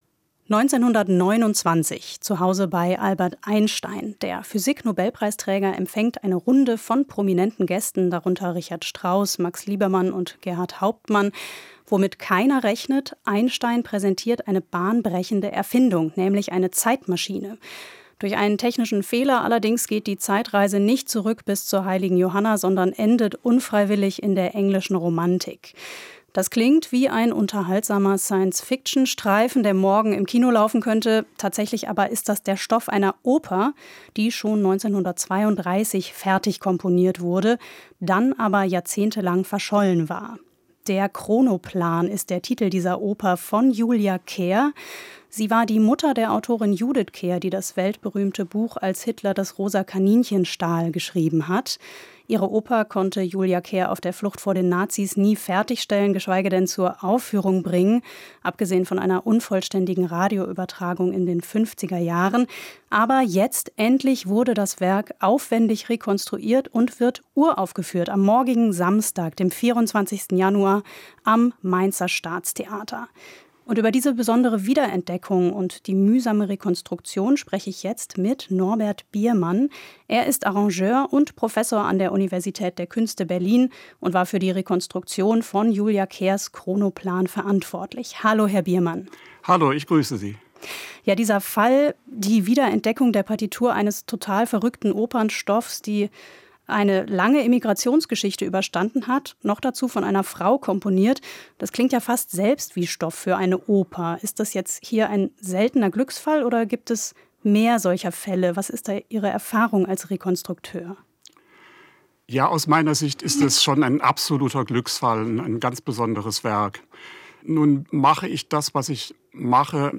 Musikgespräch
Interview mit